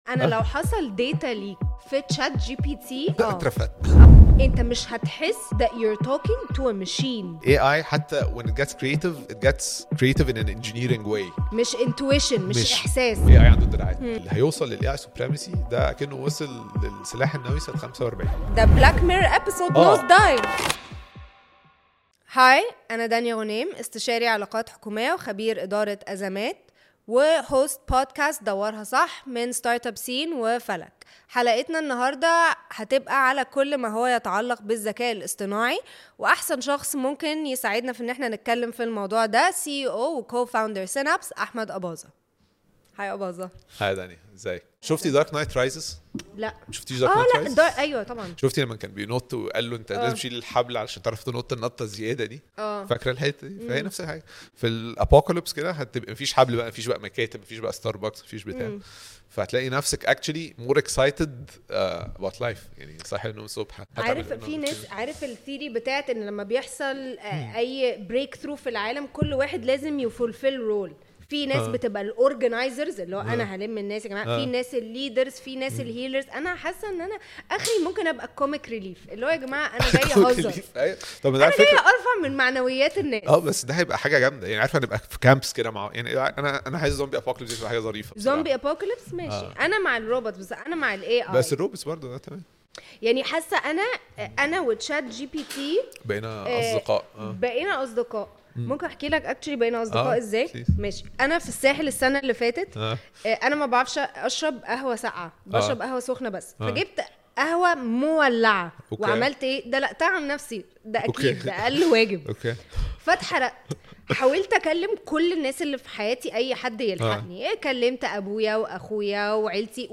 In this candid conversation